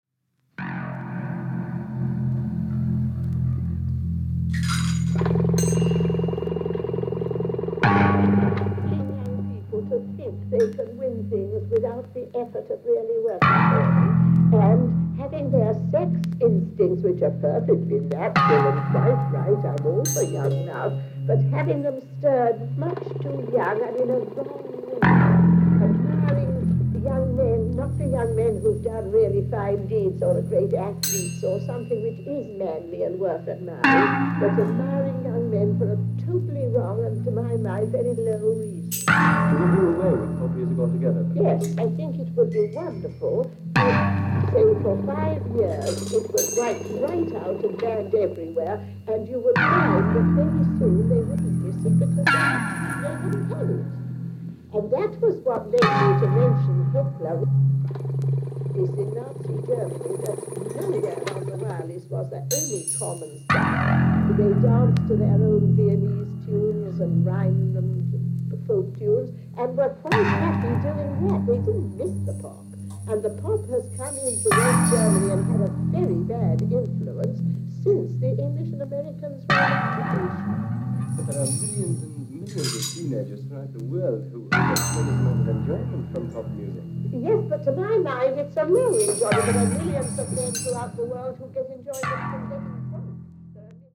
実験的な勢いがステキですね！！！